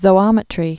(zō-ŏmĭ-trē)